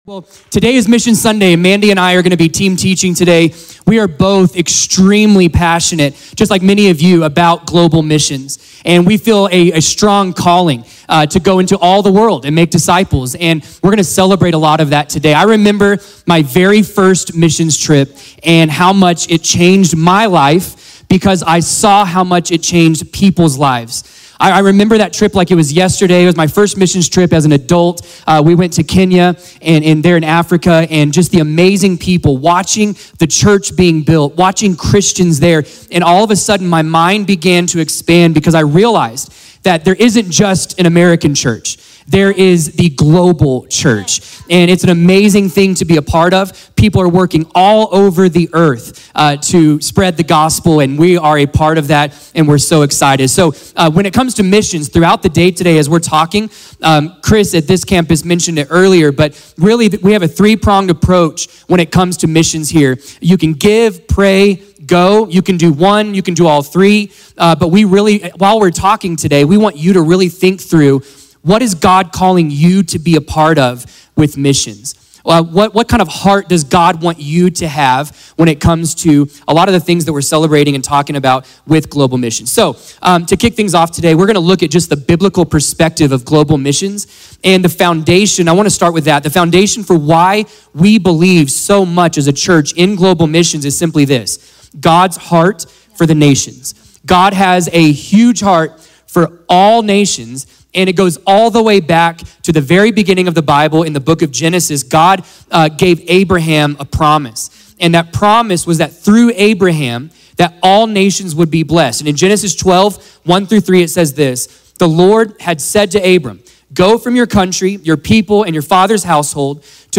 A message from the series "Mother's Day Messages."